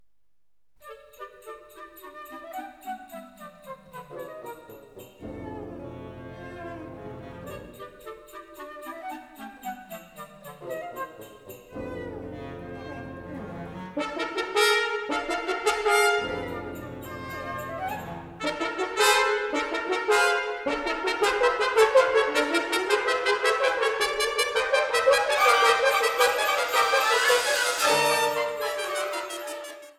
Allegretto innocente